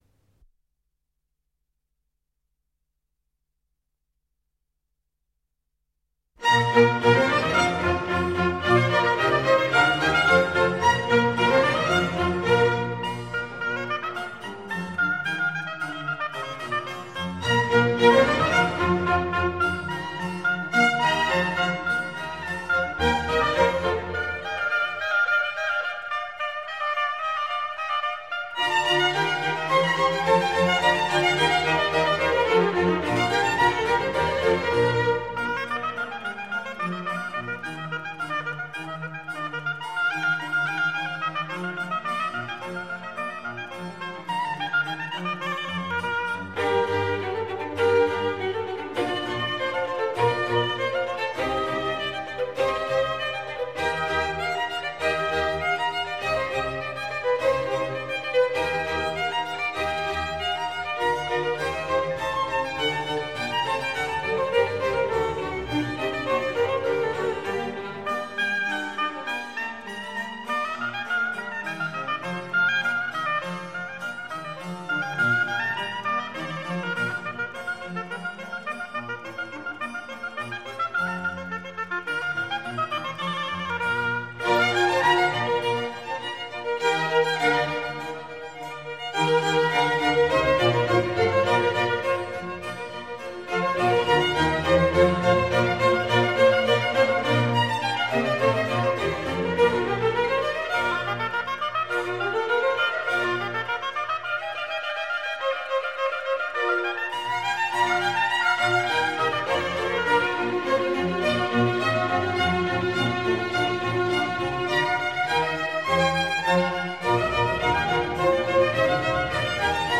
Solo concerto
in Si bemolle maggiore - I. Allegro